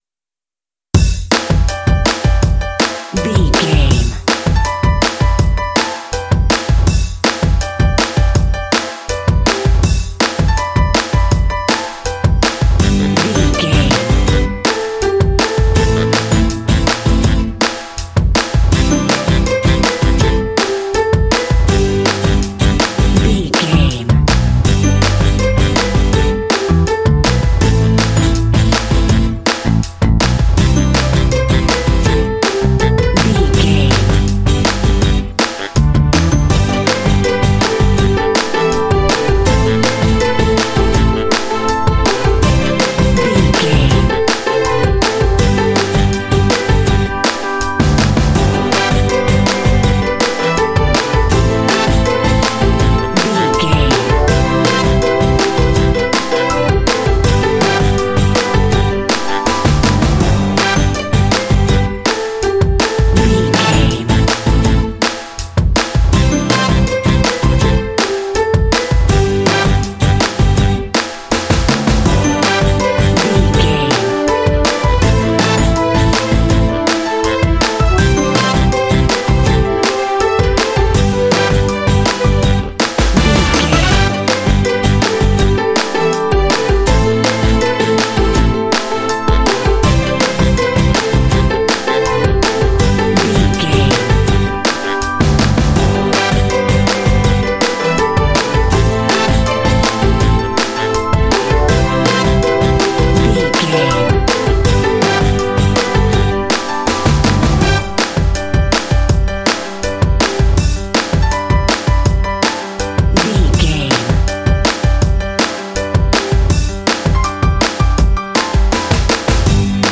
Ionian/Major
bright
uplifting
bouncy
piano
drums
acoustic guitar
strings
bass guitar
saxophone
pop
contemporary underscore